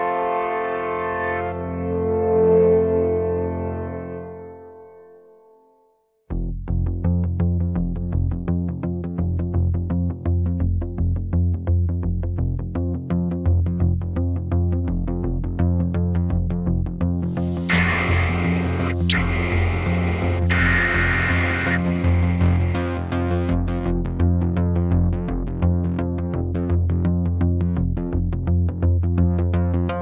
ARP Odyssey